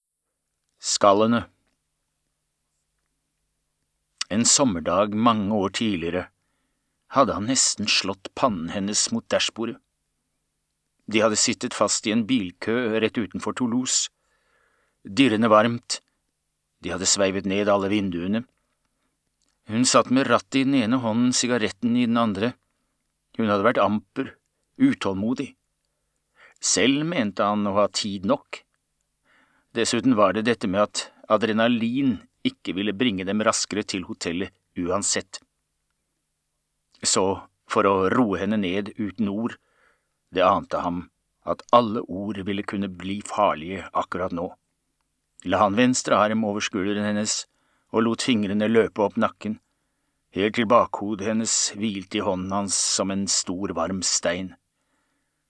Skallene (lydbok) av Ingvar Ambjørnsen